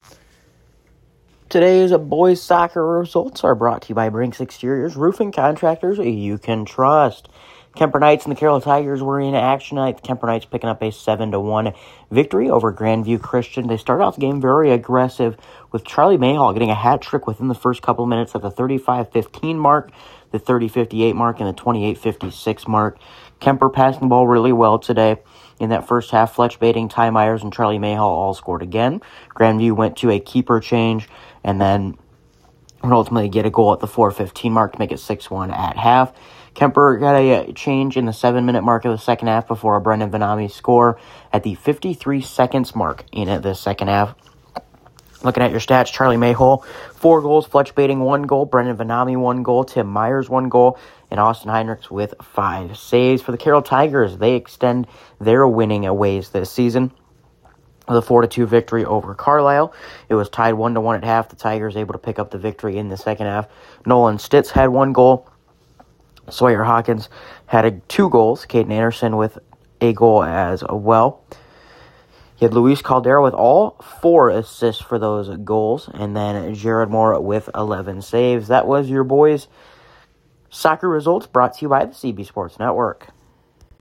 Below is an Audio Recap of Boys Soccer Results from Monday, April 20th